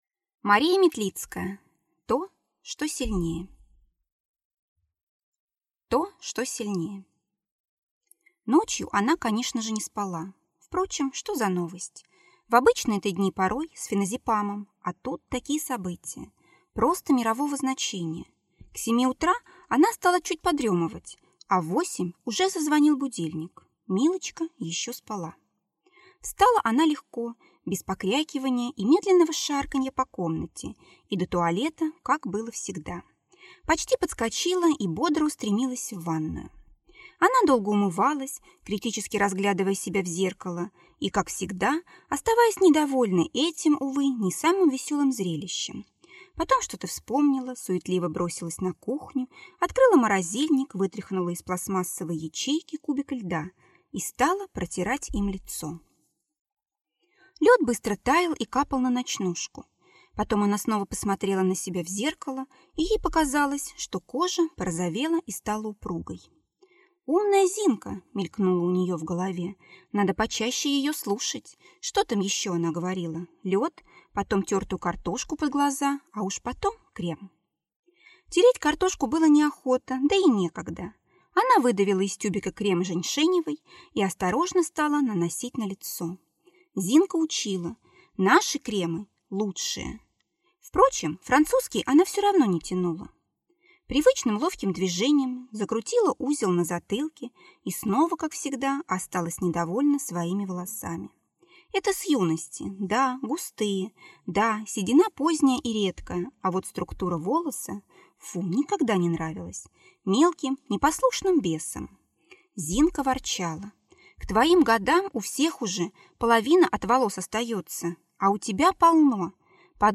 Аудиокнига То, что сильнее (сборник) | Библиотека аудиокниг